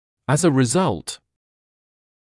[əz ə rɪ’zʌlt][эз э ри’залт]как результат…